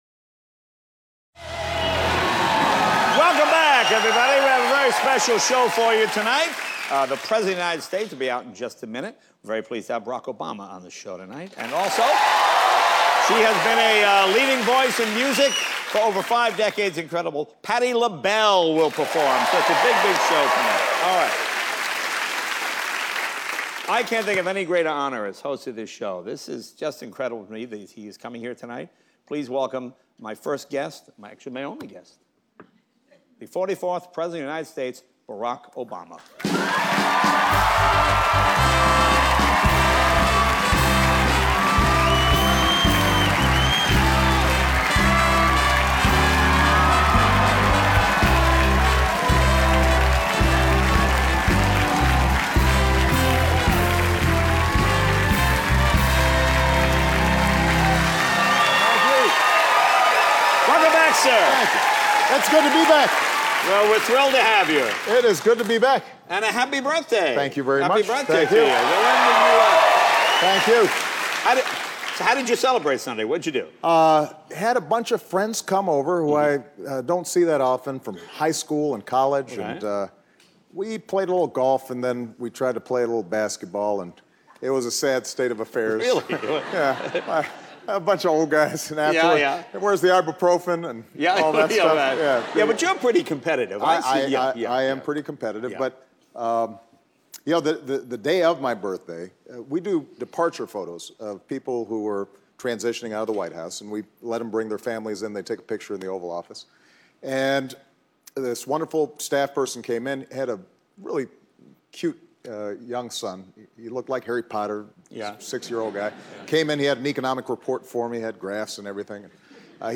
Interviewees Obama, Barack Interviewers Leno, Jay
Broadcast on NBC-TV, Aug. 6, 2013.